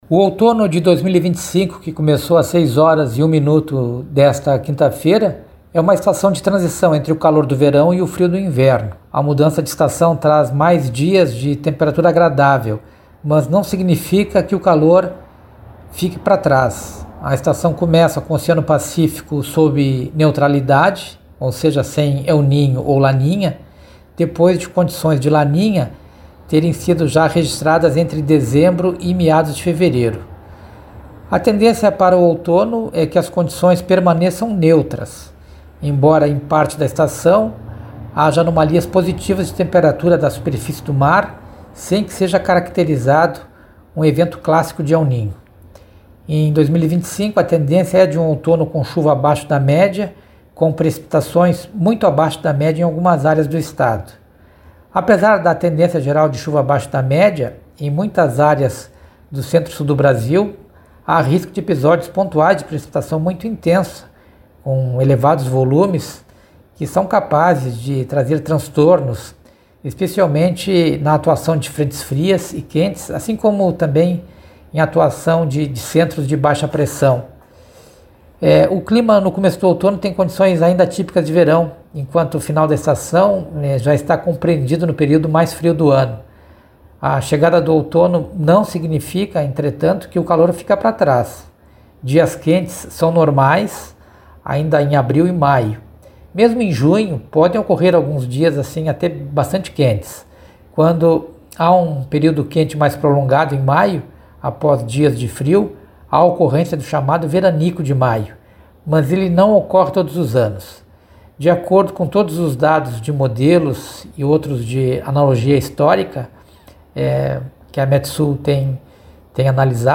Confira a explicação do meteorologista